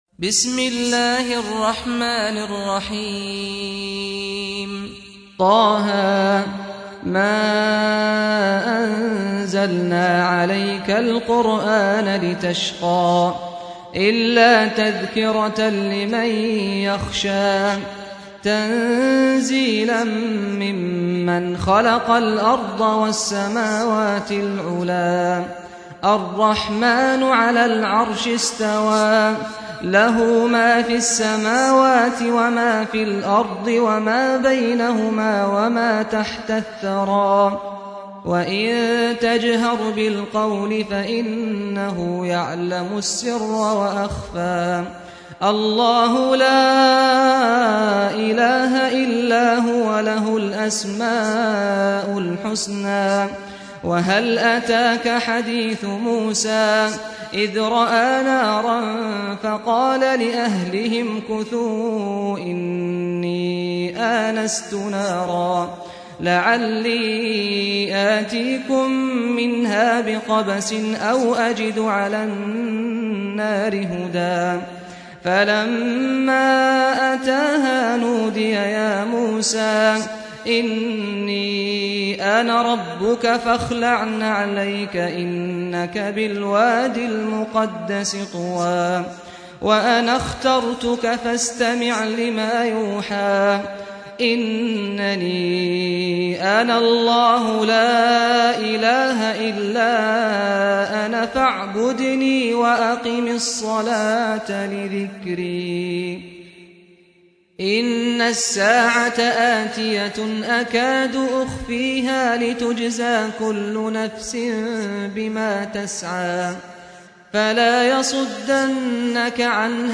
سُورَةُ طه بصوت الشيخ سعد الغامدي